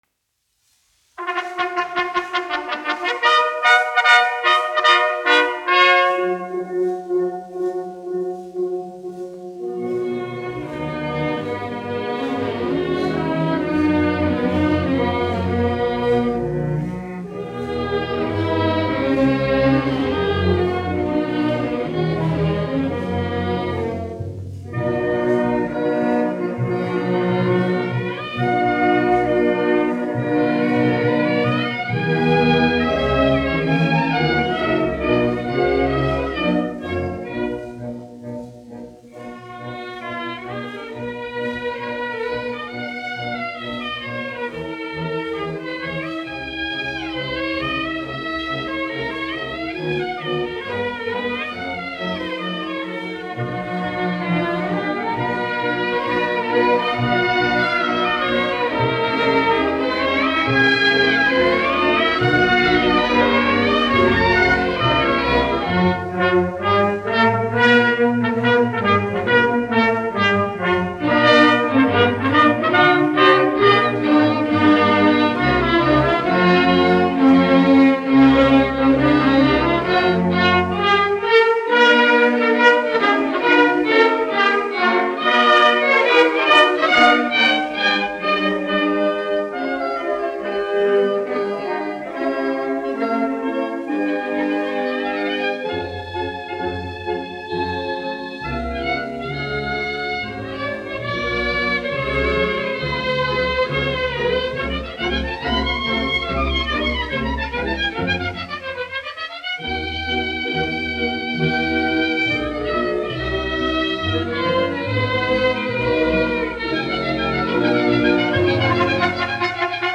1 skpl. : analogs, 78 apgr/min, mono ; 25 cm
Operas--Fragmenti
Skaņuplate